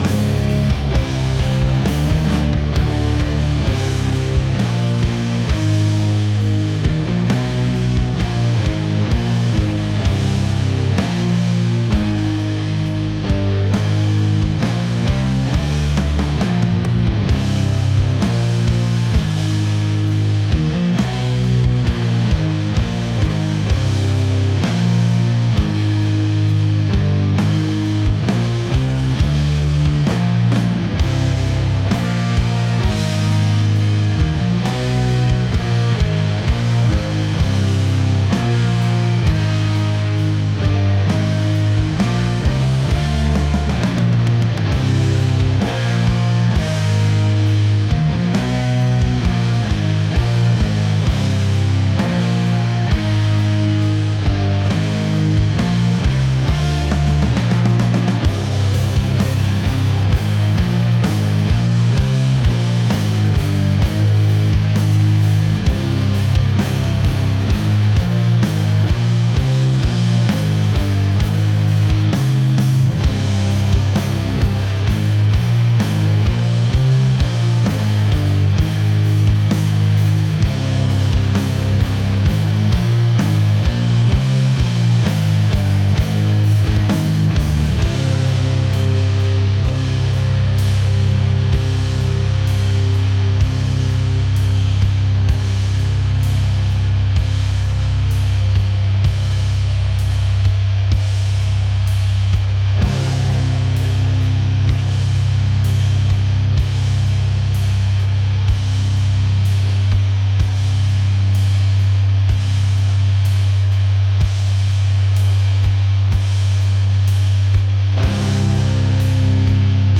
heavy | metal